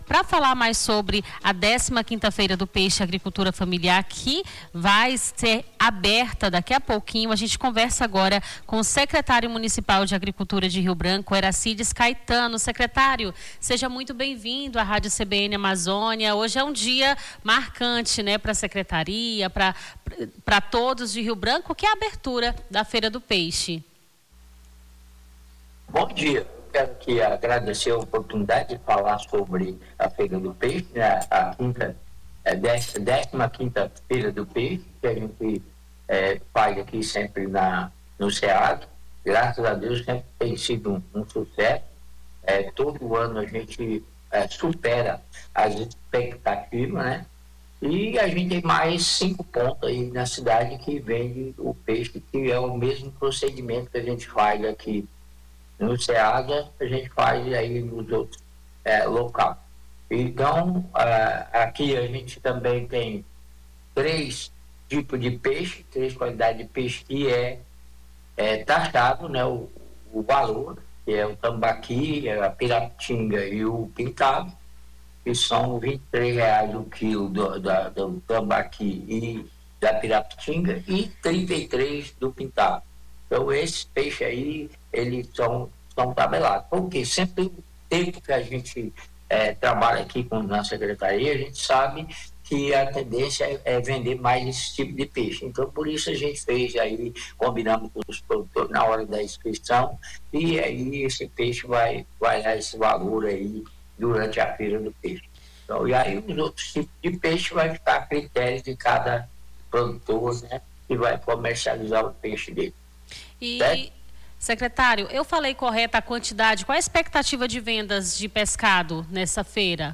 Na manhã desta quarta-feira, 01, conversamos com o secretário municipal de agricultura Eracides Caetano sobre as expectativas para a feira do peixe 2026.